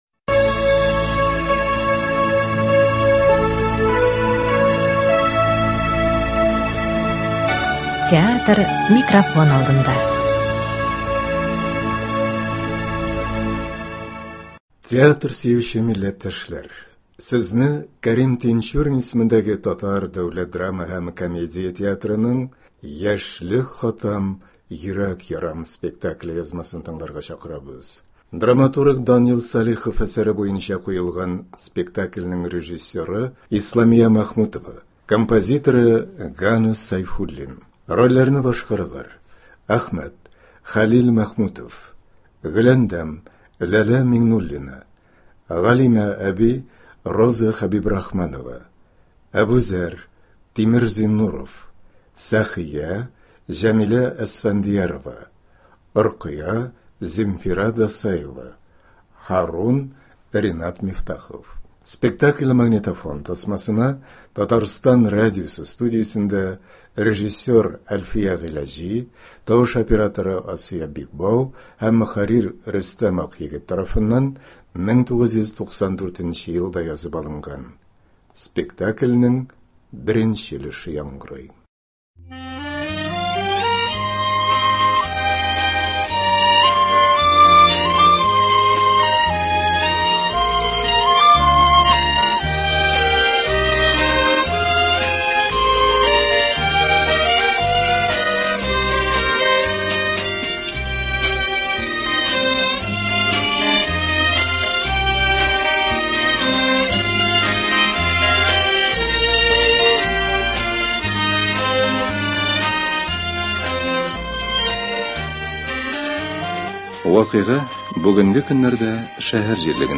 К.Тинчурин исемендәге Татар дәүләт драма һәм комедия театры спектакле.